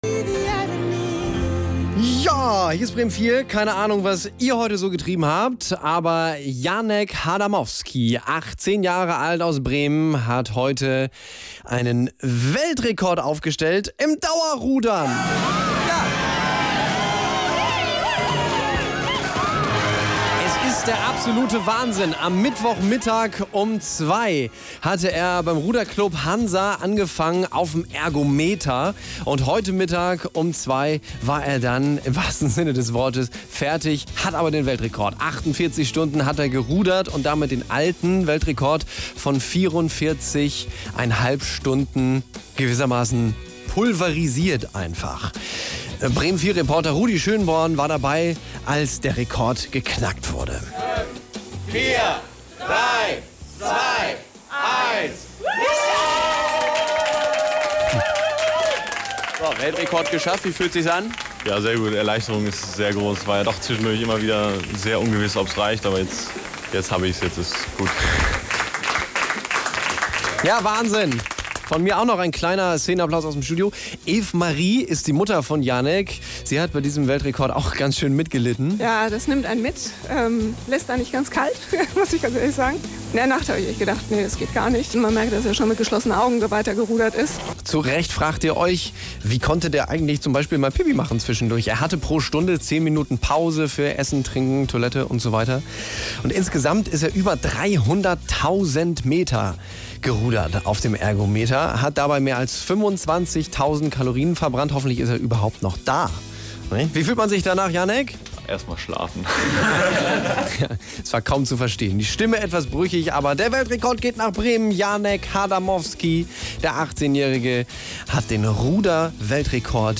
Sendungsmitschnitt